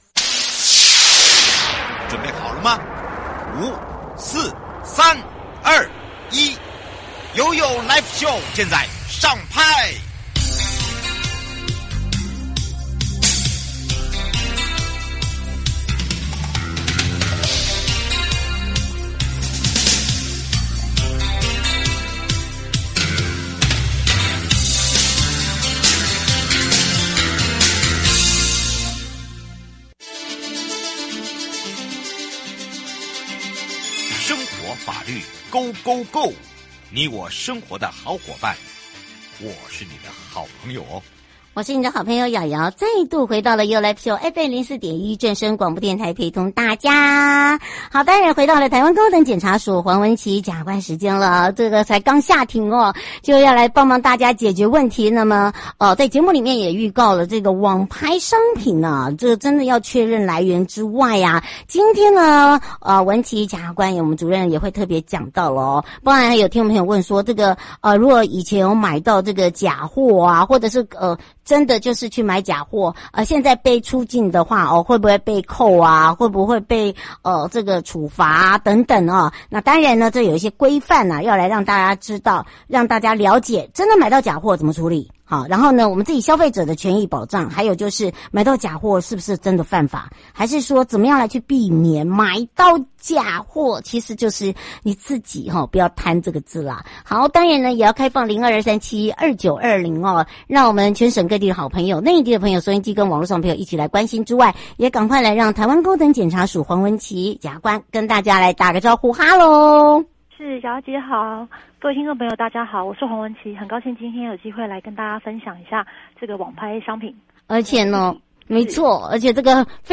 受訪者： 台灣高等檢察署 黃紋蓁檢察官 節目內容： 主題: 網拍海外真品，沒有侵權問題？